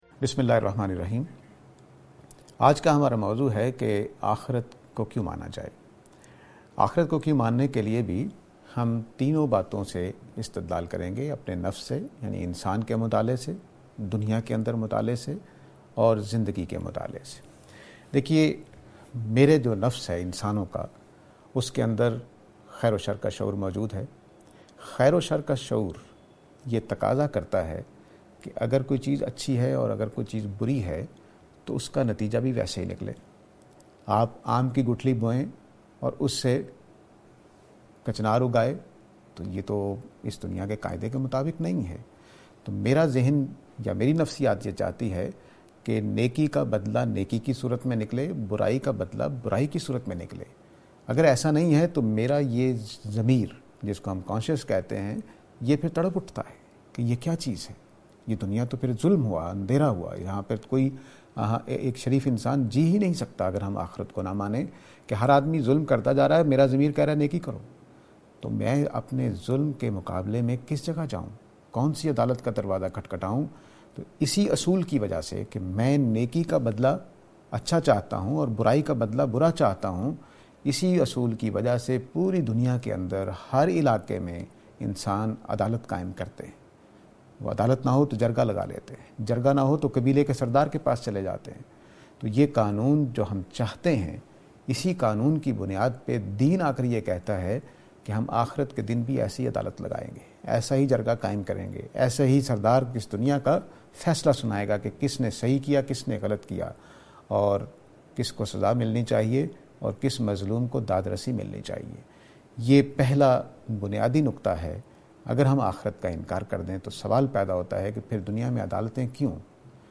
This lecture is and attempt to answer the question "Why the Hereafter?".